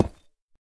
Minecraft Version Minecraft Version snapshot Latest Release | Latest Snapshot snapshot / assets / minecraft / sounds / block / deepslate / place5.ogg Compare With Compare With Latest Release | Latest Snapshot